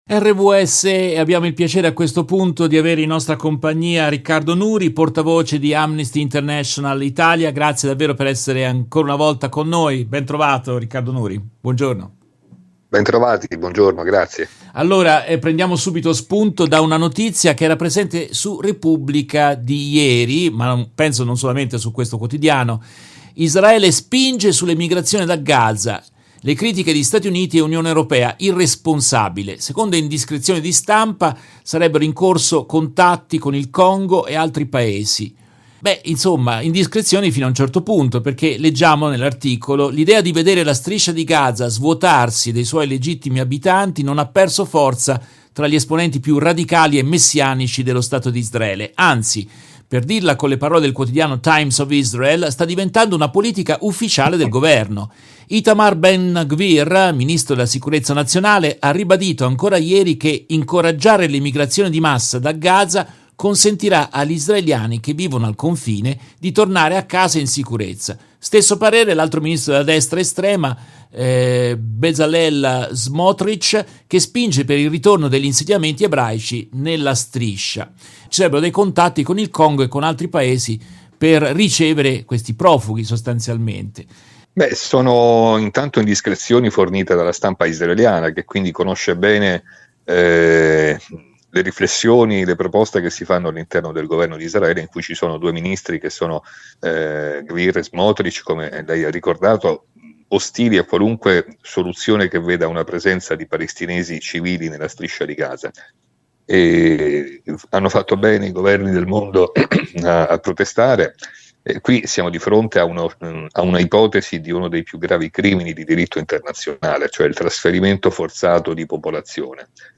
In questa intervista tratta dalla diretta RVS del 05 gennaio 2024